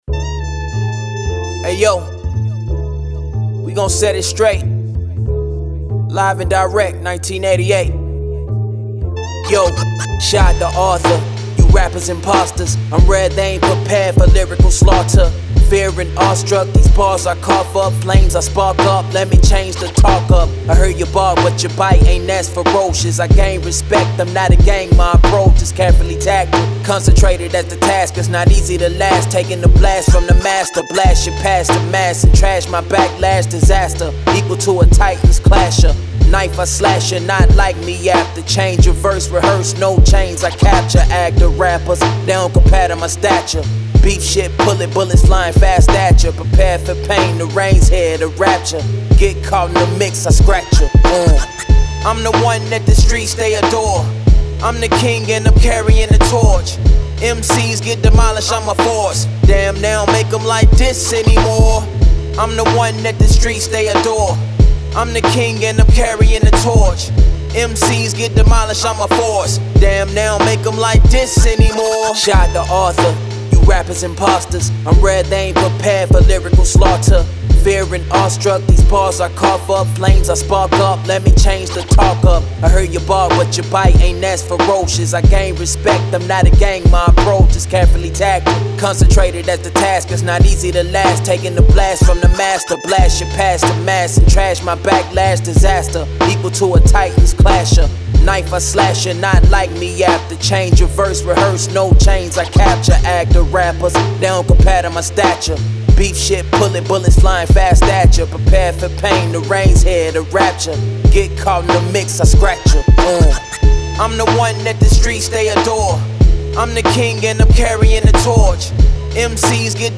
Hip Hop, 80s
D minor